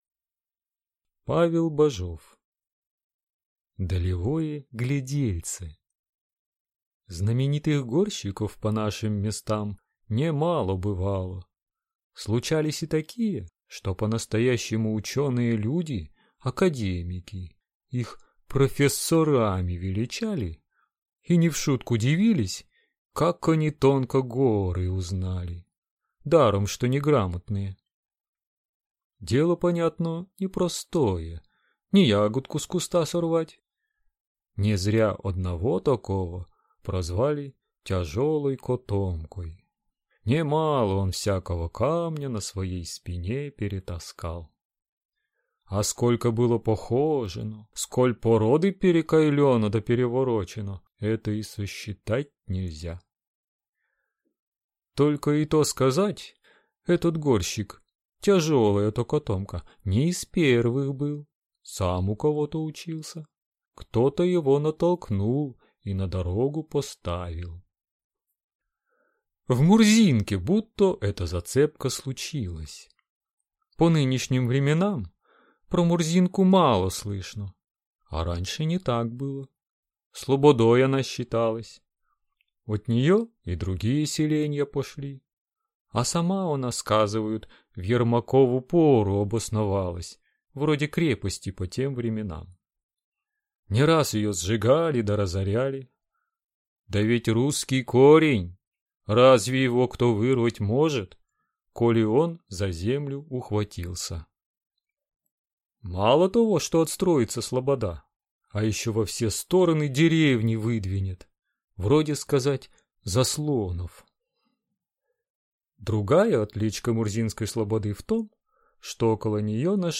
Аудиокнига Далевое глядельце | Библиотека аудиокниг
Прослушать и бесплатно скачать фрагмент аудиокниги